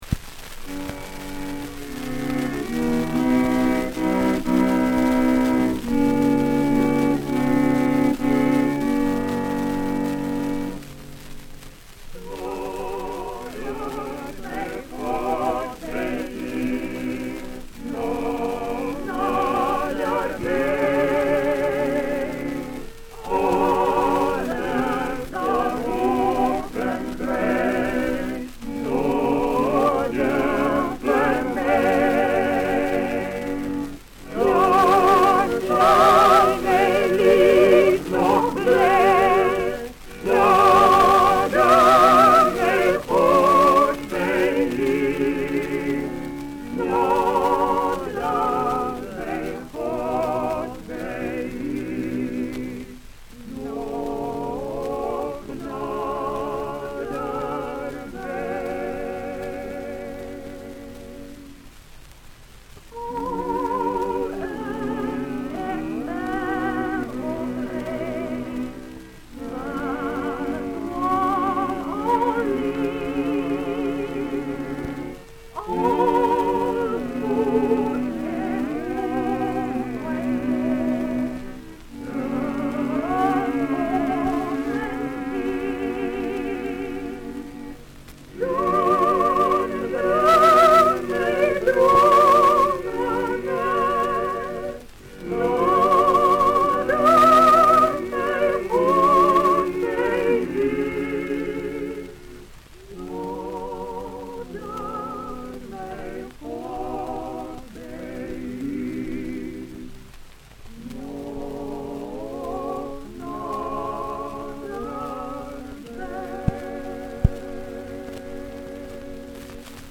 recorded 1938c
78 rpm
ORPHEUS KRISTELIKE KWARTET